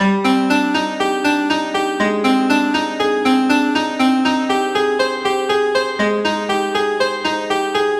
rain.wav